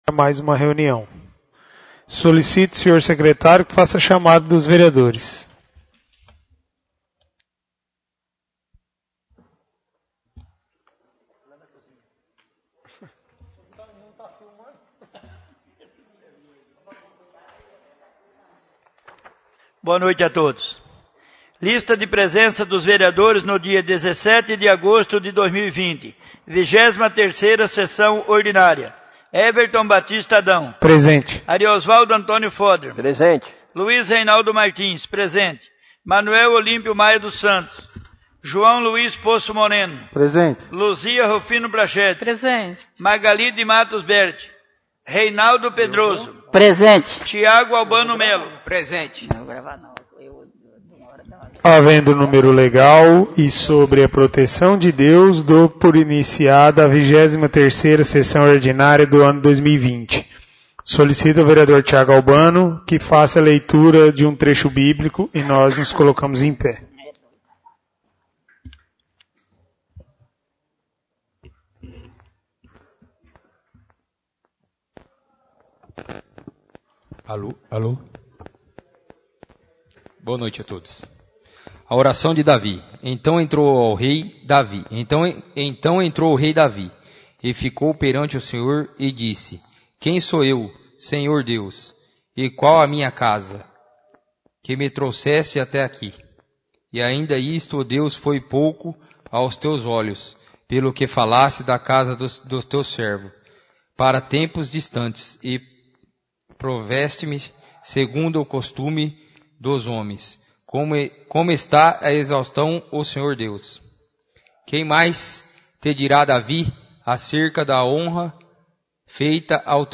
23° sessão ordinária